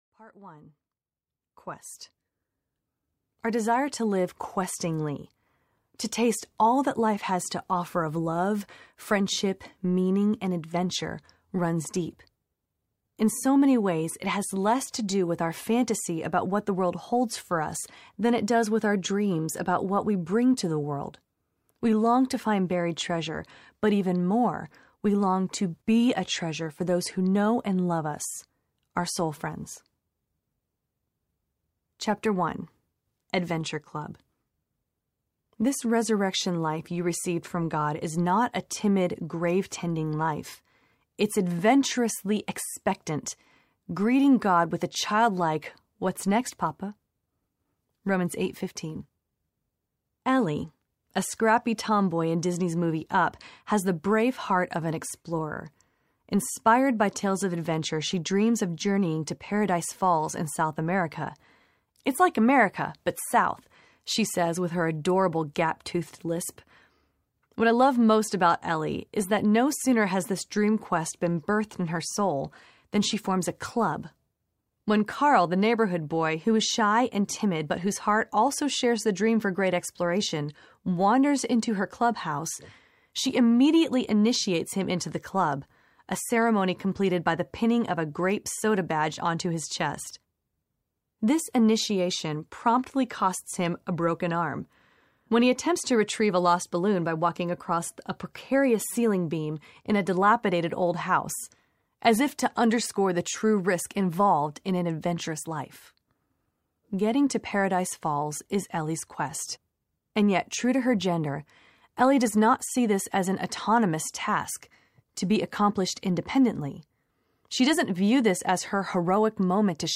Soul Friends Audiobook
5.4 Hrs. – Unabridged